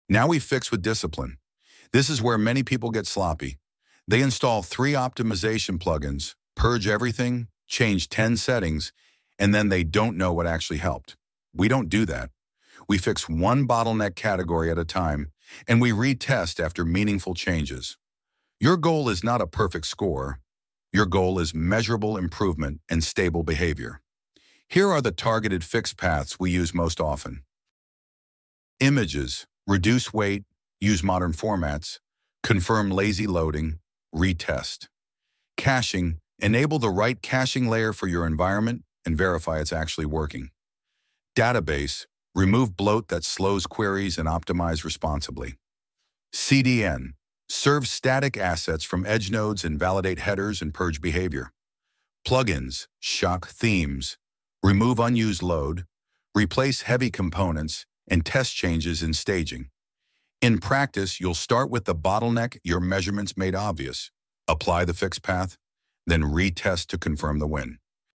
Lesson-2.1-Step-4-VO-full.mp3